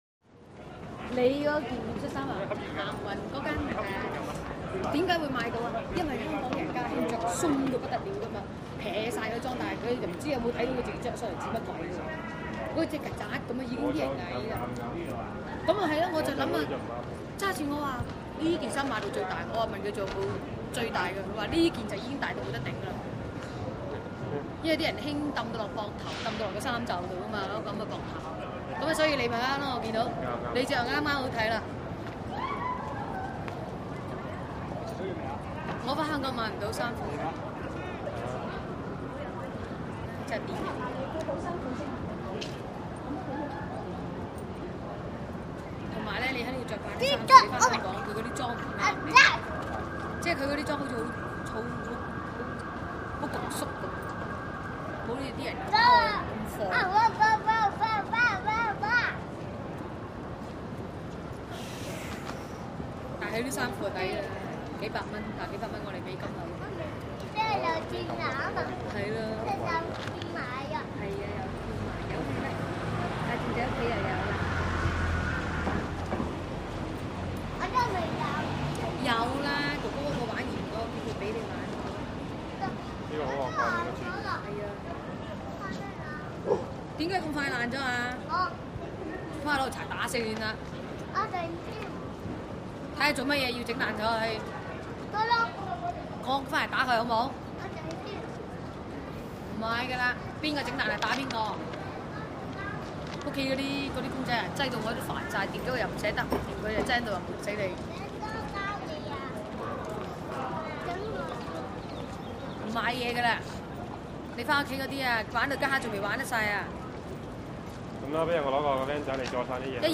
Chinatown Sidewalk Walla, Close Woman And Child With Sparse Male Walla Medium. Distant Walla Movement, Traffic, And Short Siren Or Alarm.